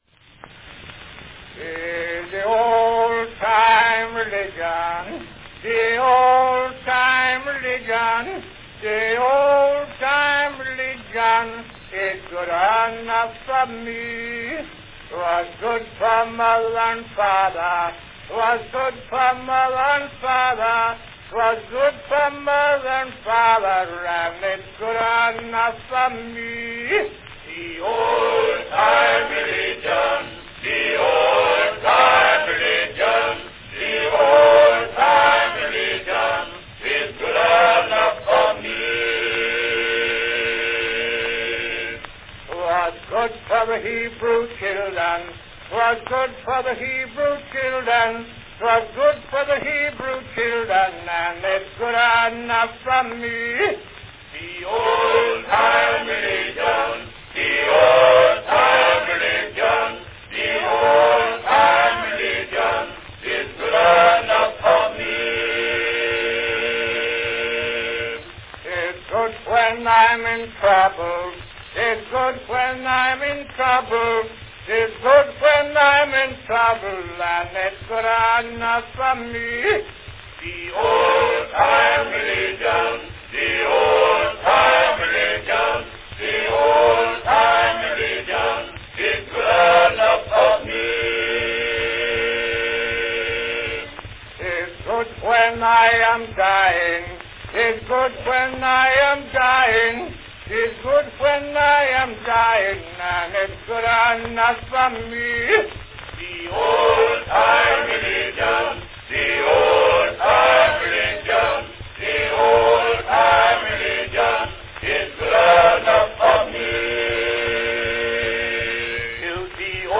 Cylinder of the Month
Category Quartette
This hymn is of negro origin, but owing to the fact that its words and melody stir the popular heart, the Southern whites have introduced it into their church services.   Unaccompanied.